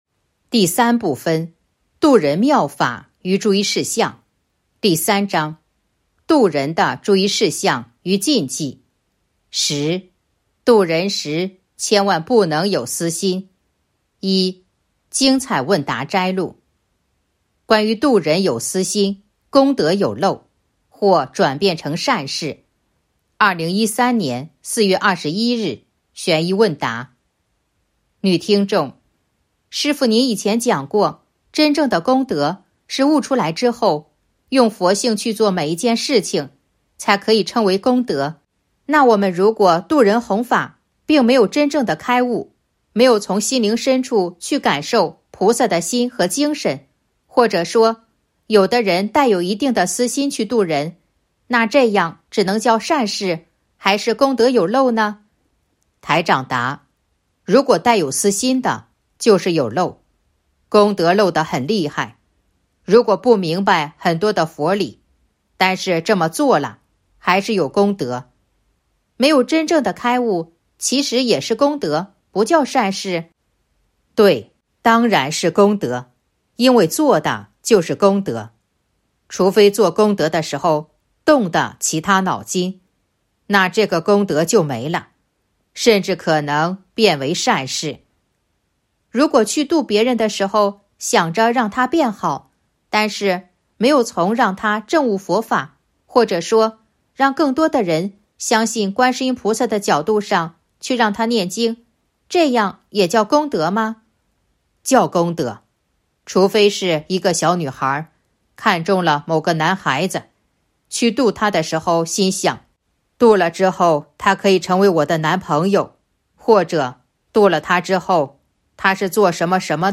066.1. 精彩问答摘录《弘法度人手册》【有声书】